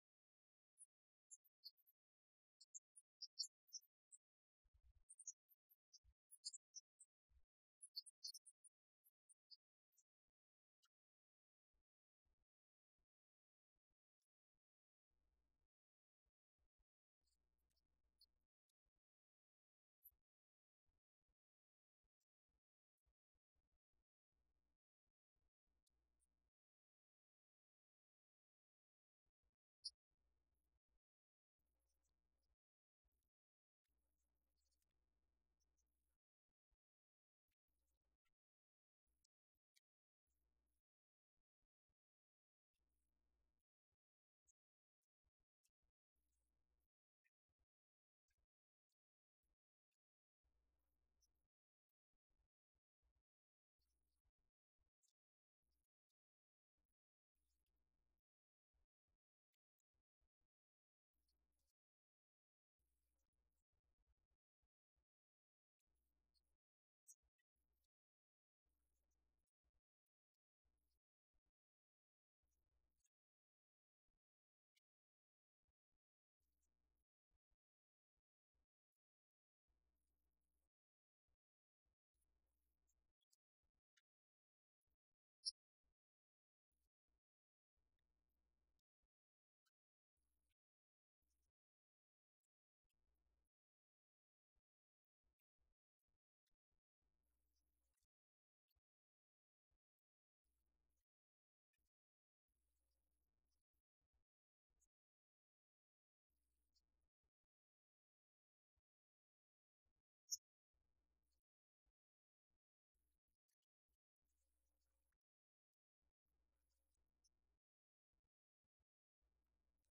36-49 Service Type: Easter « Good Friday 2025